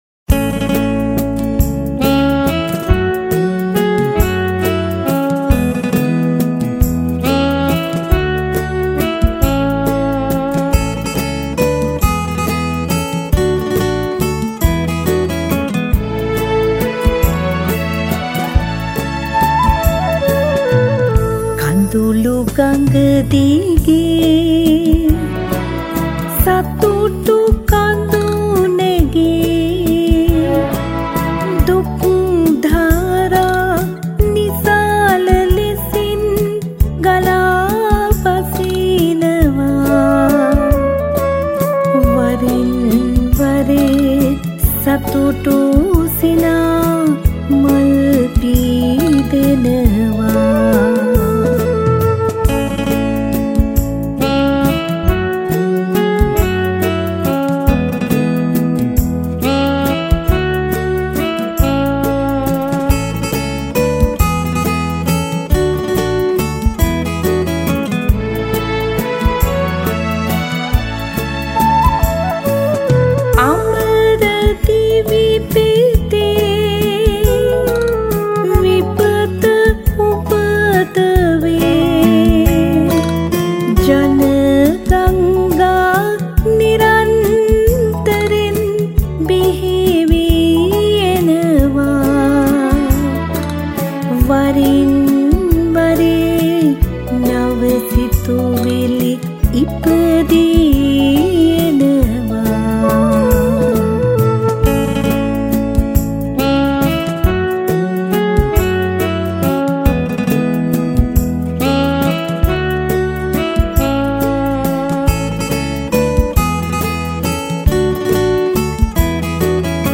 at the Sage Studio, Sri Lanka